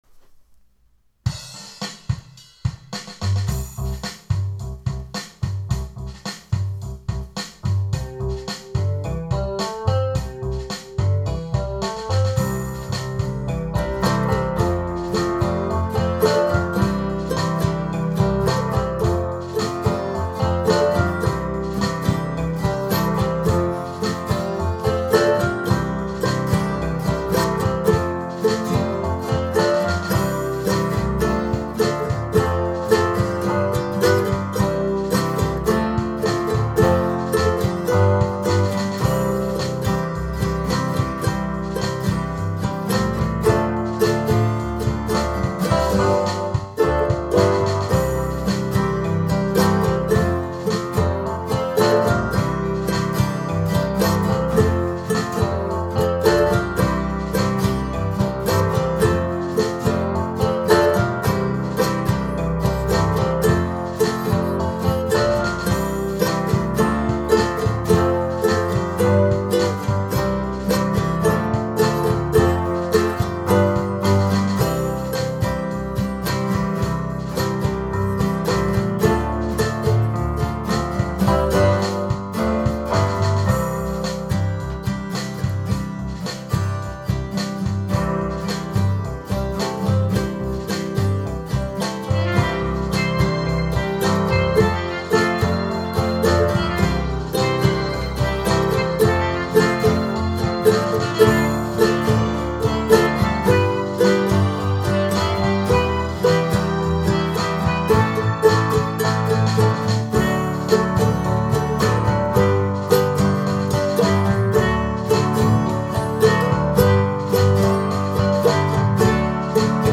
practice track without vocals